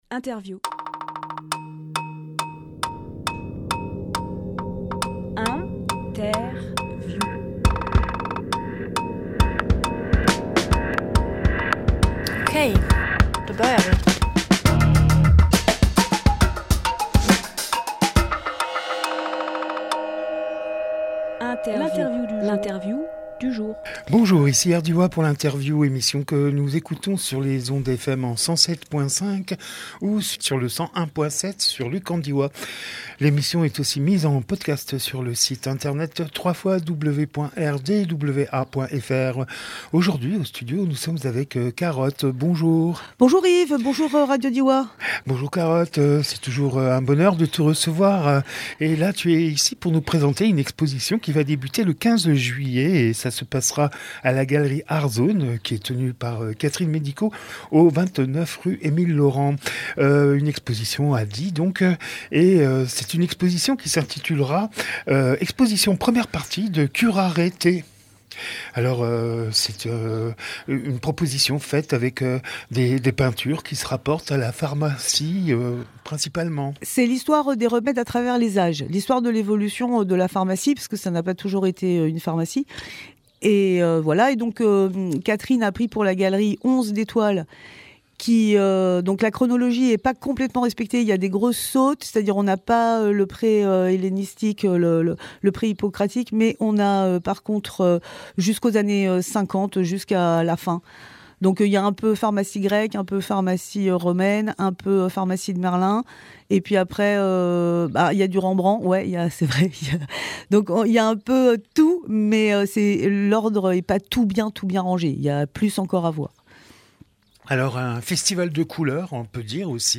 Interview
13.07.22 Lieu : Studio RDWA Durée